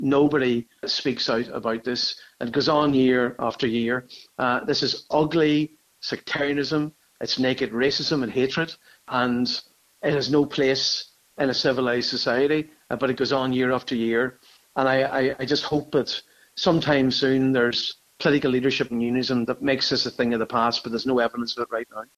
Donegal Deputy Padraig MacLochlainn says Unionist leaders need to call it out: